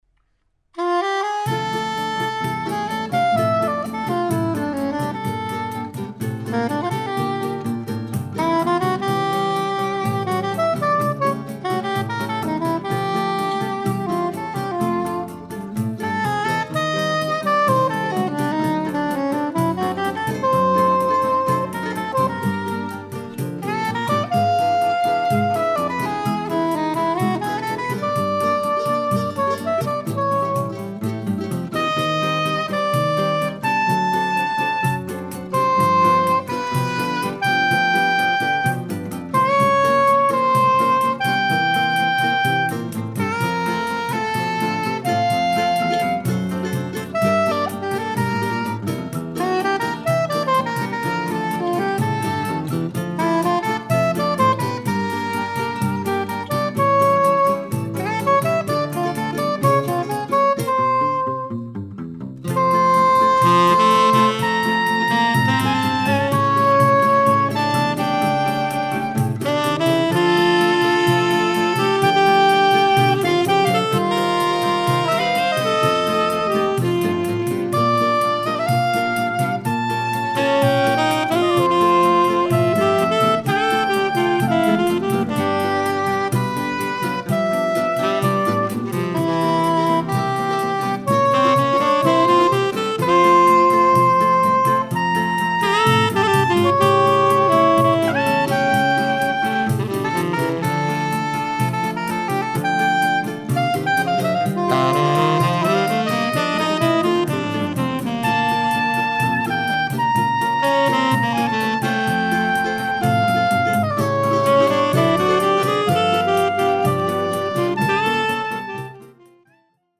tenor and soprano sax